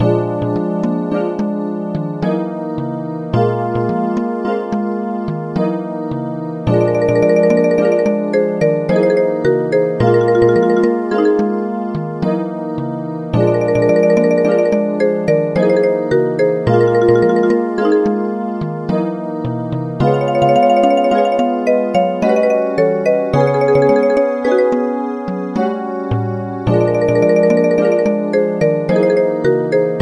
Athetlic plains theme